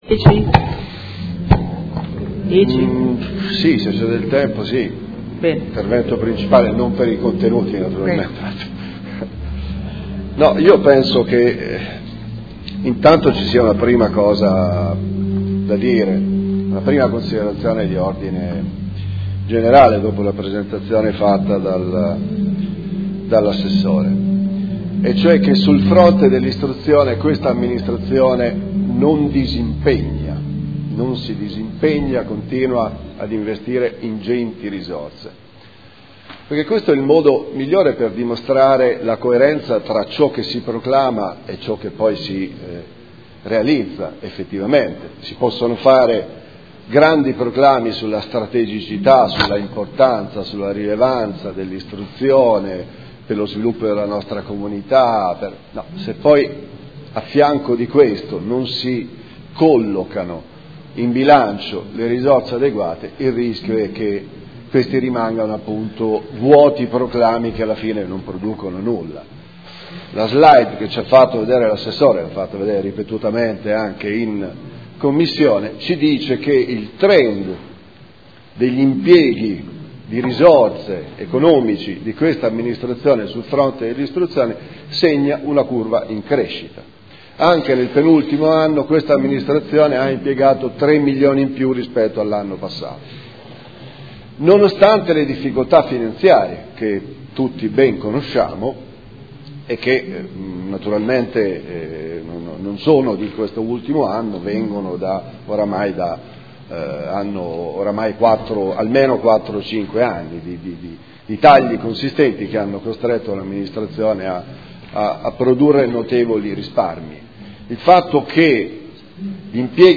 Seduta del 17/09/2015. Dibattito sul progetto "Educare Insieme", piano della buona scuola a Modena